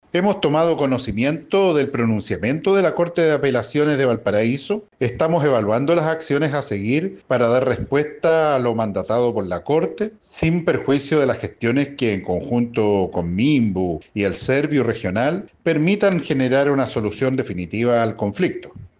Ante ello, Yanino Riquelme, delegado presidencial regional de Valparaíso, comentó que tomaron conocimiento del pronunciamiento de la justicia y se encuentran evaluando las acciones a seguir con la finalidad de generar una solución definitiva al conflicto.